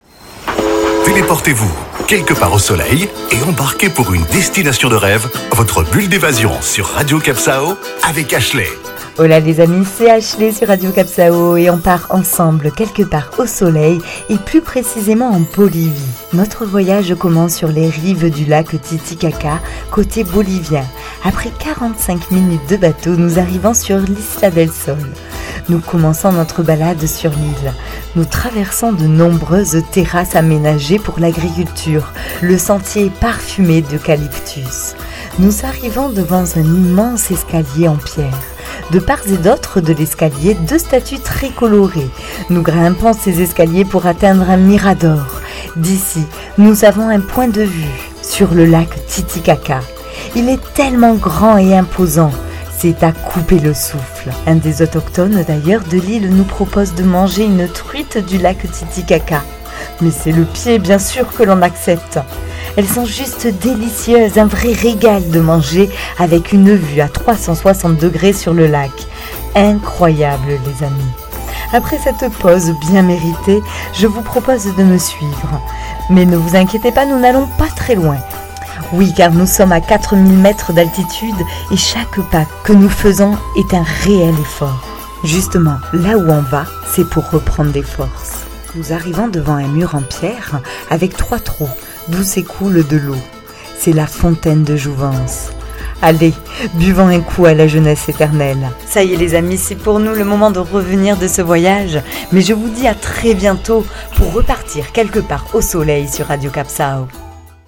Carte postale sonore : arpentez le relief de la plus grande île du lac Titicaca, avec le soleil pour compagnon, et partez à la rencontre de ses habitants.